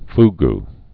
(fg)